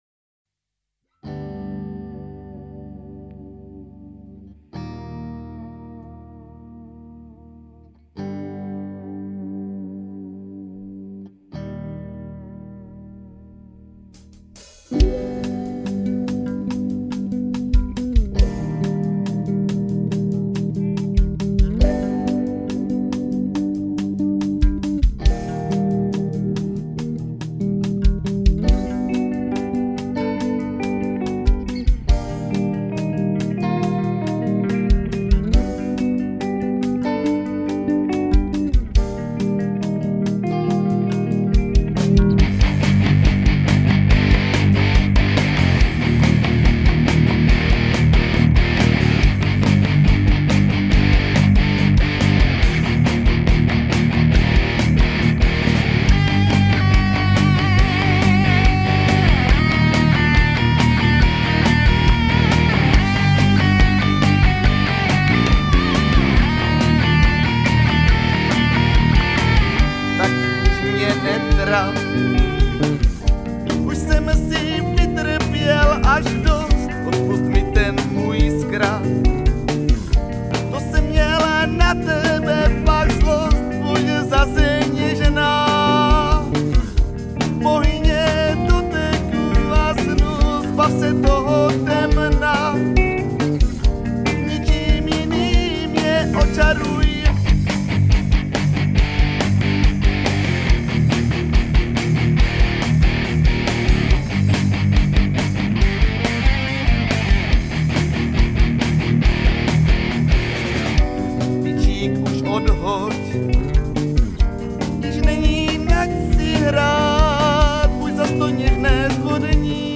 Nahráno to bylo za výjimečných okolností, zcela zdarma a pochopitelně ve spěchu, což jde i hodně slyšet.
Zpěvák má hlas poměrně zajímavej, ale zvláště v dlouhých tónech špatně intonuje (kolísá) a bacha na obrácené přízvuky (v češtině by měl být přízvuk vždy na první slabiku).
Muzika zní seriózně, kytary štěkaj dž dž dž dž a on do toho pomalu jódluje.